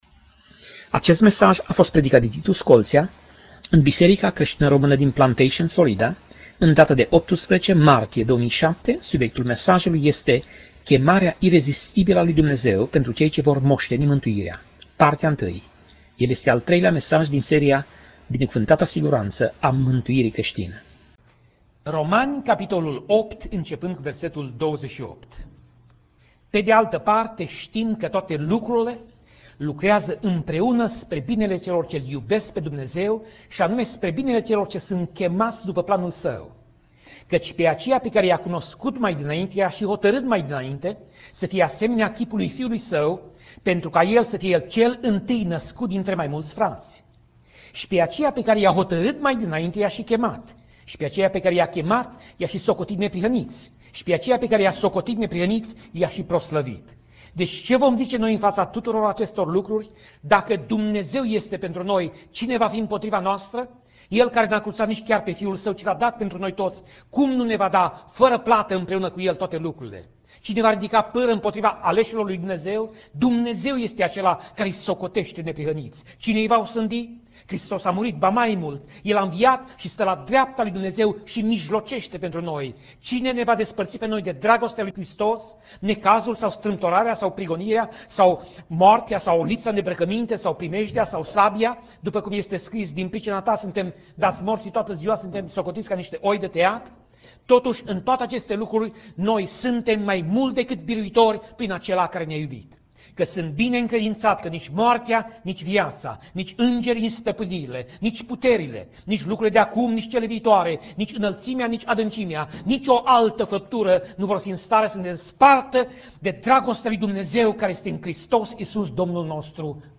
Pasaj Biblie: Romani 8:28 - Romani 8:30 Tip Mesaj: Predica